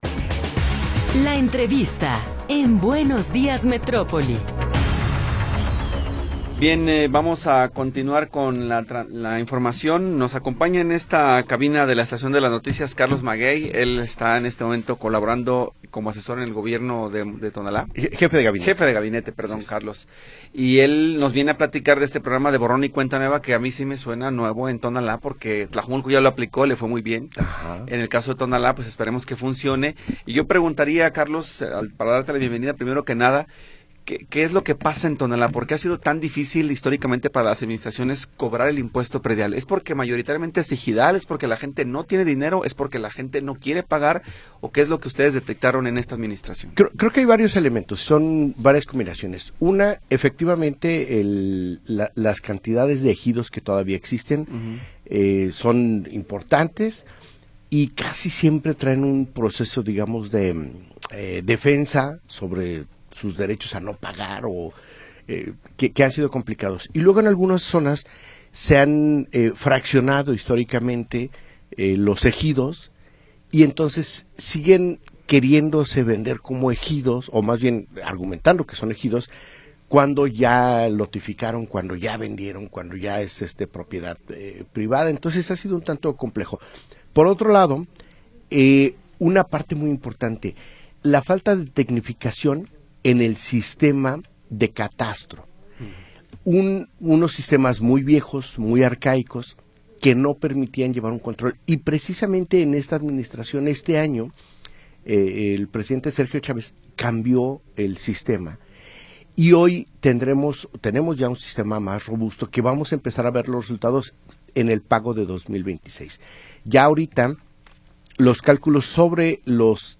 Entrevista con Carlos Martínez Maguey